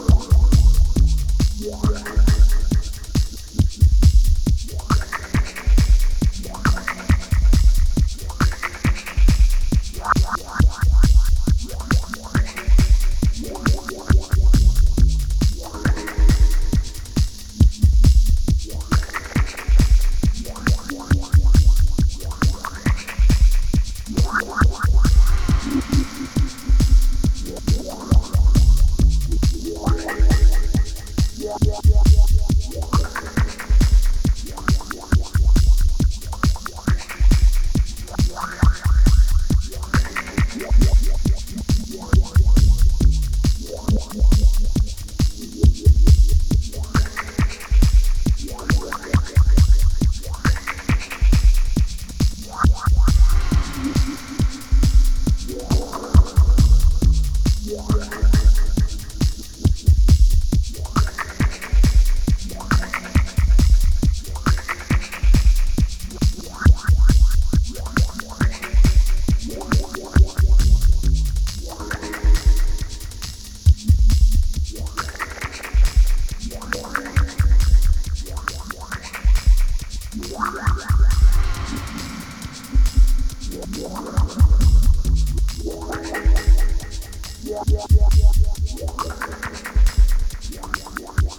しなやかに蠢くサブベースと浮遊するダブコードに意識を飛ばされるステッパーズ感覚のA1。